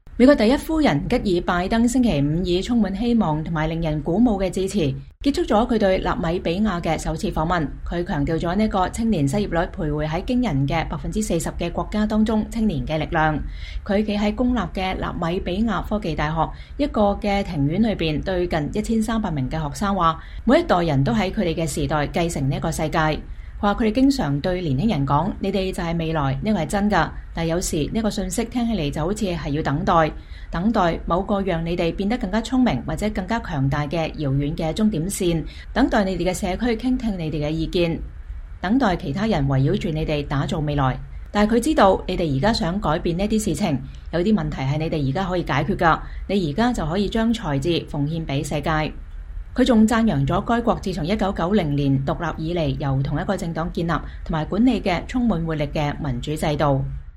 美國第一夫人吉爾·拜登2023年2月24日在納米比亞科技大學向學生致辭。
她站在公立的納米比亞科技大學一個蔭蔽的庭院裡對近1300名學生說道：“每一代人都在他們的時代繼承這個世界。”